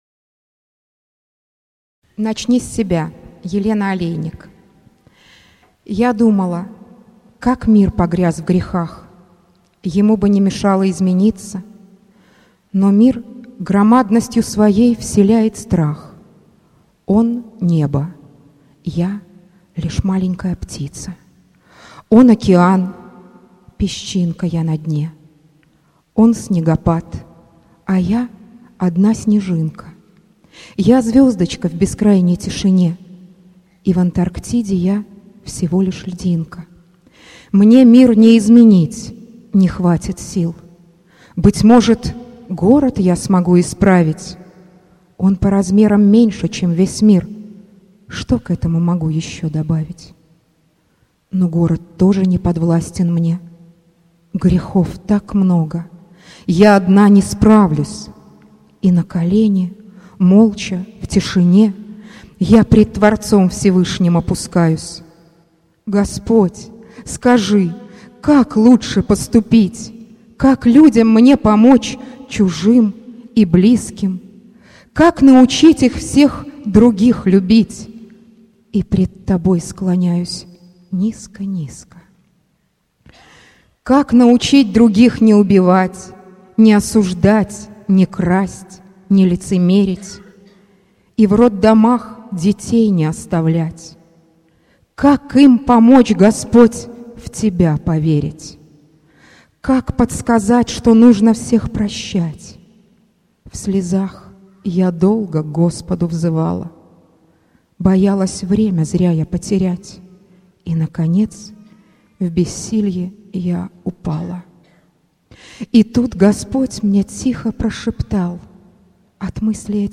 Начни с себя. Стихотворение